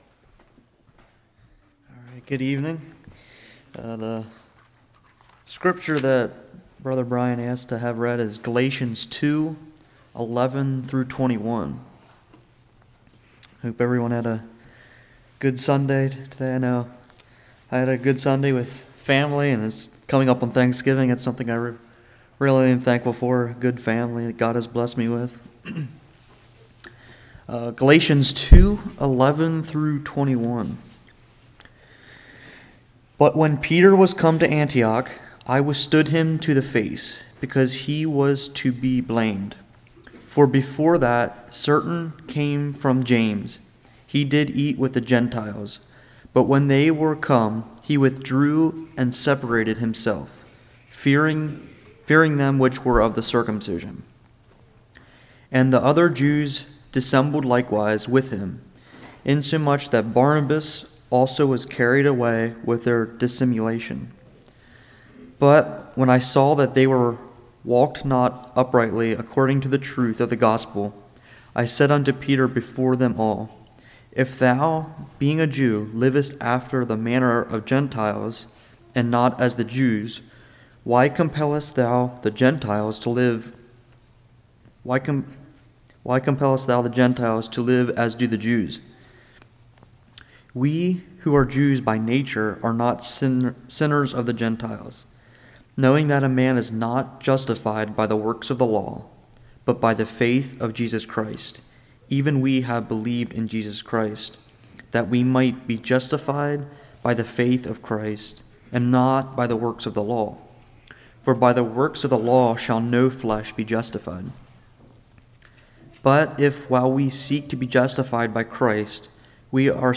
Galatians 2:11-21 Service Type: Evening Do You Care About People?